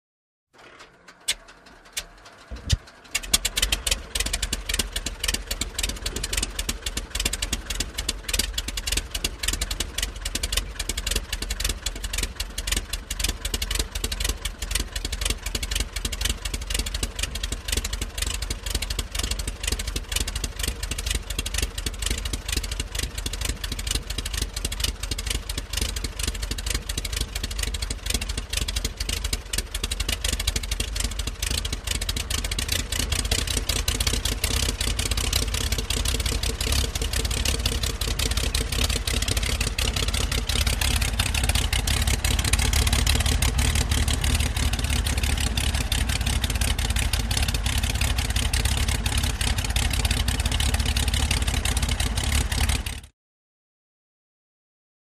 Beechcraft: Start / Idle: Medium; Beechcraft, 1978 E55 Baron. Sputtering Start With Popping Exhaust, Builds Up Speed, Steadies At Sharp, Raspy Idle. Close Perspective. Prop Plane.